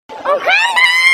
oljandra olhandra alejandra sound effect Meme Sound Effect
Category: Reactions Soundboard
oljandra olhandra alejandra sound effect.mp3